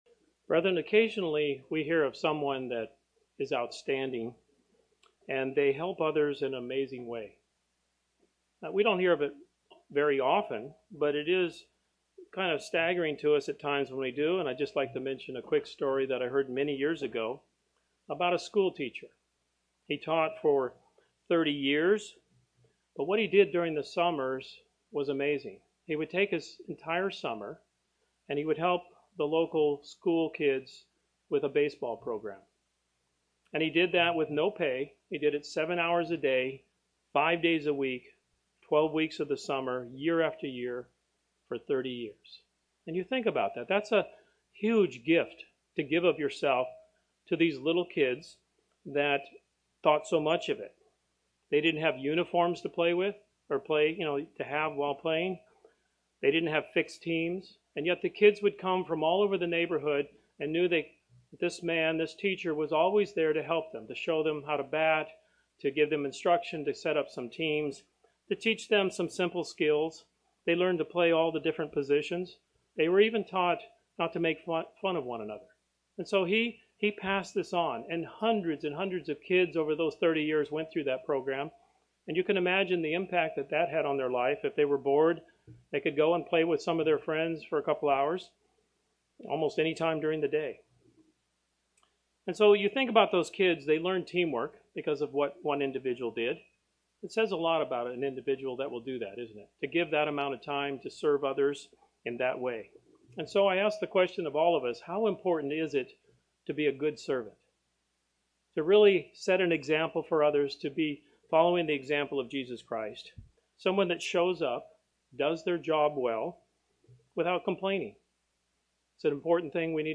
This sermon discusses three vital attributes of a godly servant.